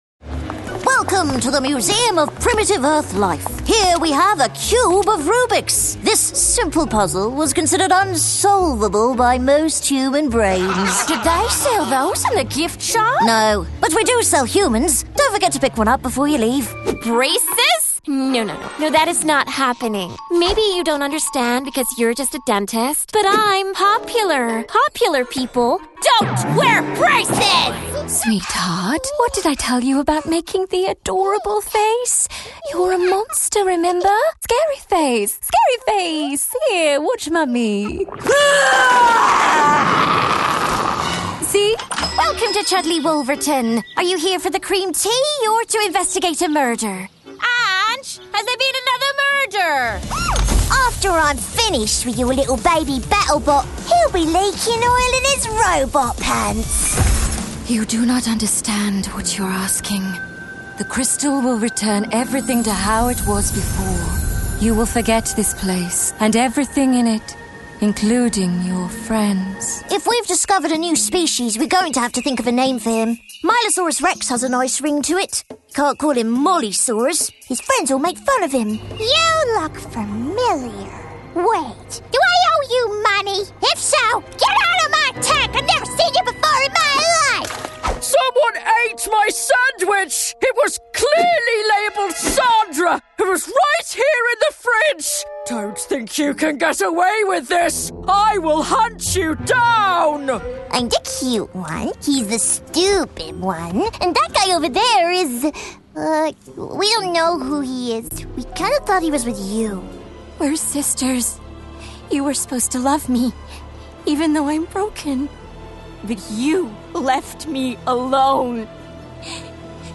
Animation
Mitreißend, inspirierend, natürlich und klar mit erstaunlicher Bandbreite und Vielseitigkeit.
Mikrofone: Neumann U87, Neumann TLM 102, Sennheiser MKH 416
Kabine: Doppelwandige, maßgeschneiderte Gesangskabine von Session Booth mit zusätzlichen Paneelen von EQ Acoustics, Auralex und Clearsonic.